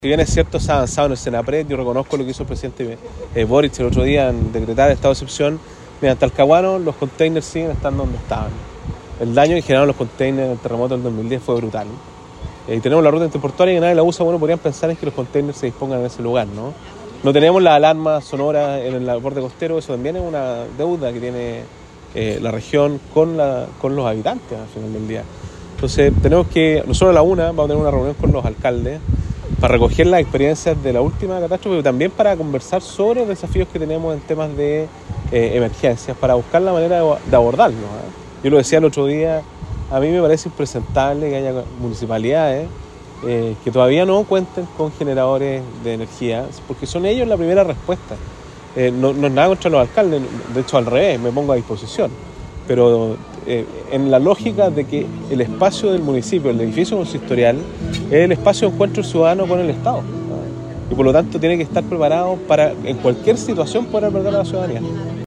Durante la mañana de este jueves 27 de febrero, el gobernador del Biobío, Sergio Giacaman, se trasladó hasta la Plaza de la Ciudadanía de Dichato, para participar de una ceremonia de conmemoración del terremoto del 27 de febrero de 2010, organizada por el Comité de Vivienda de esa localidad.